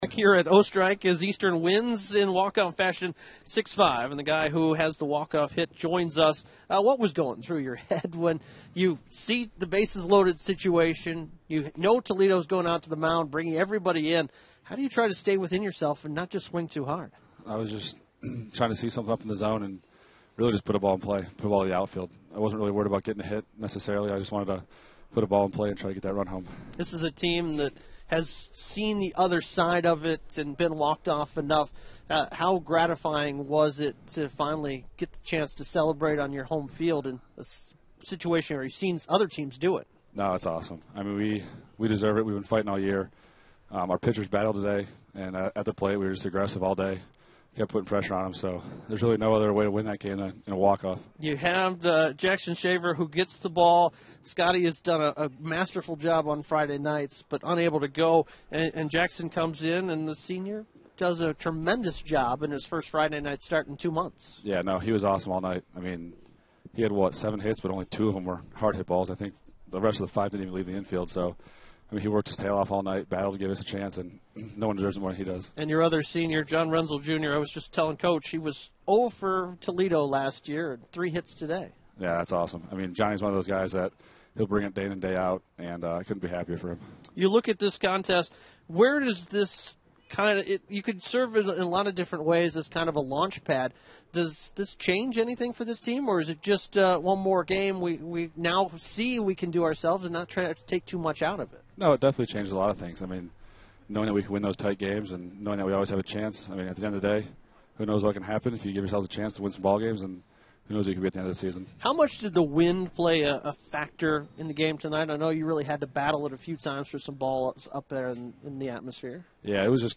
Post Game Audio: